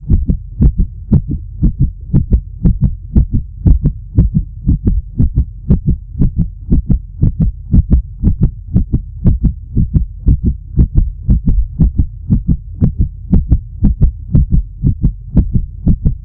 heartbeat_fast.wav